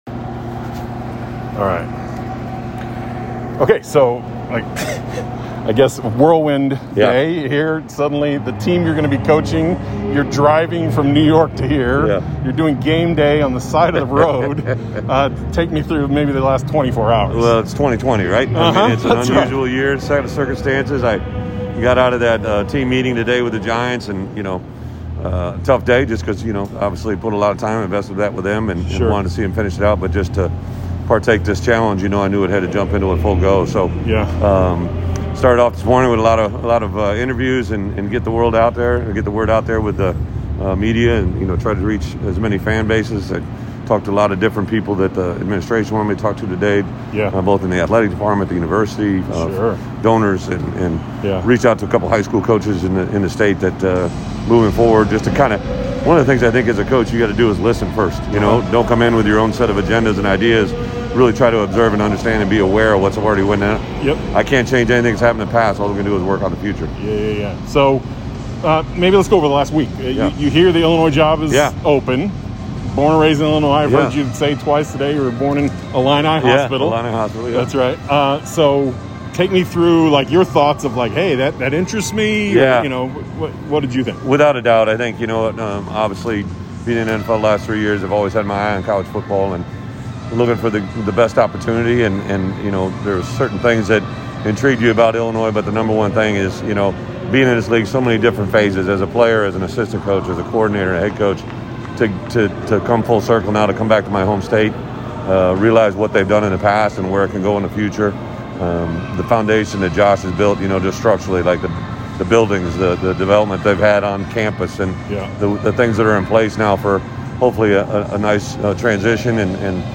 Headliner Embed Embed code See more options Share Facebook X Subscribe (Bonus Episode) Had the chance to interview new Illini coach Bret Bielema at the Penn State game.